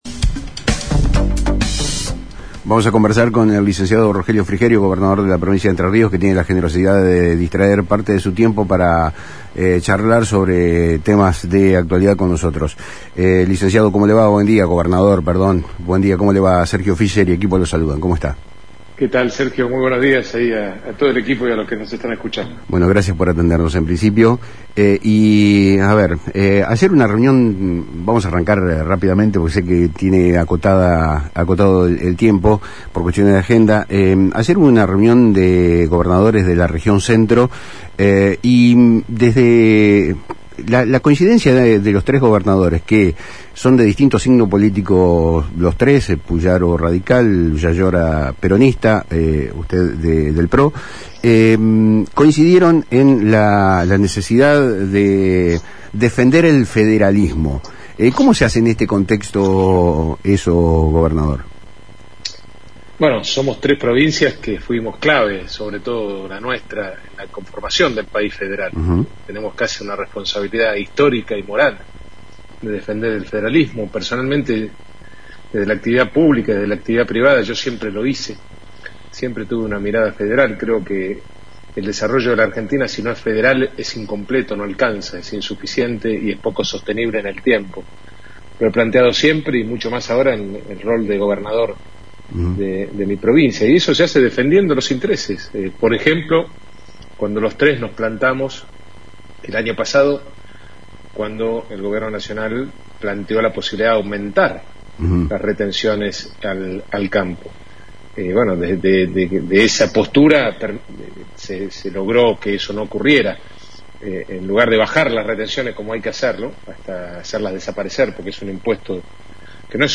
En una entrevista concedida a Palabras Cruzadas por FM Litoral de Paraná, el gobernador de Entre Ríos, Rogelio Frigerio, desgranó los desafíos y las estrategias de su gestión, con un claro enfoque en la defensa del federalismo y la ardua tarea de transformar una provincia con décadas de inercia política.